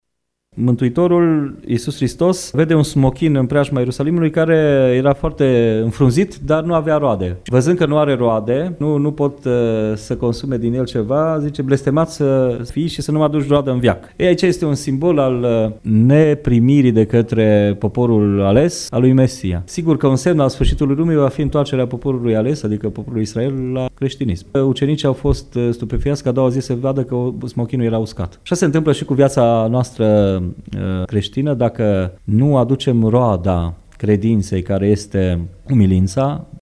Ce s-a întâmplat din punct de vedere istoric în această zi, ne spune preotul ortodox din Tg Mureş